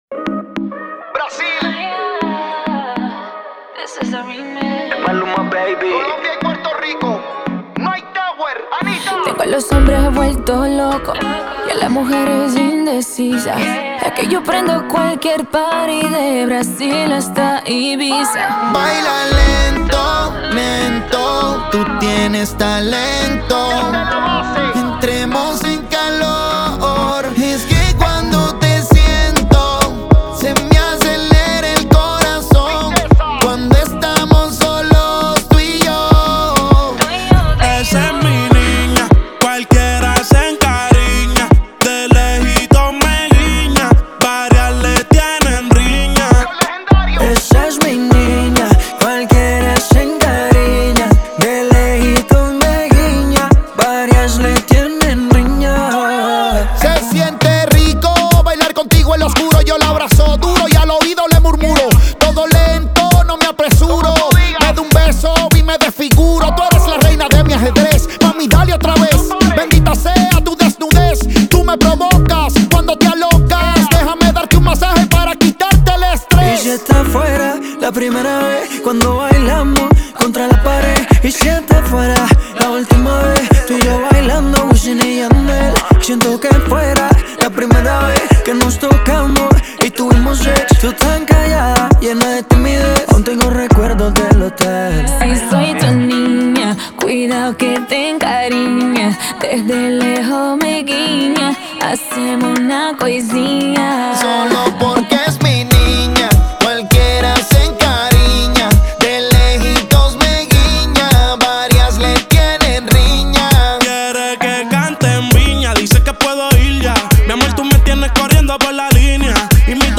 это яркий трек в жанре реггетон